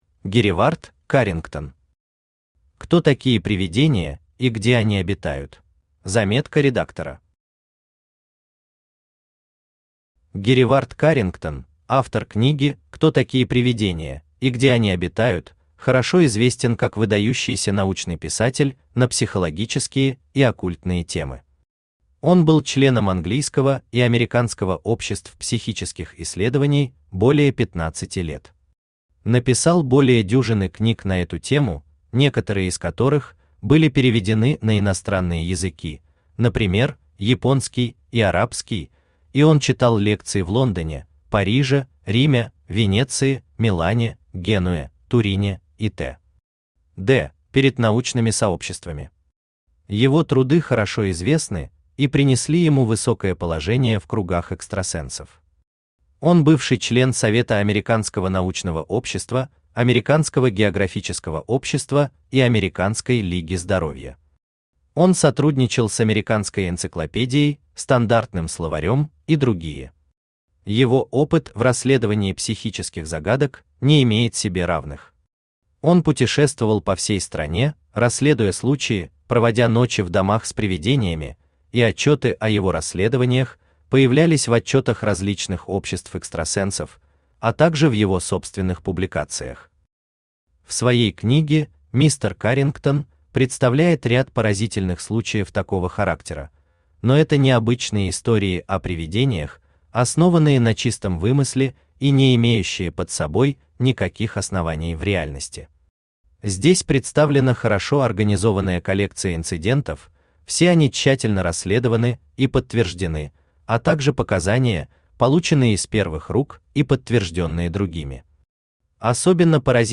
Аудиокнига Кто такие привидения, и где они обитают | Библиотека аудиокниг
Aудиокнига Кто такие привидения, и где они обитают Автор Геревард Каррингтон Читает аудиокнигу Авточтец ЛитРес.